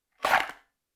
Draw Weapon Sound.wav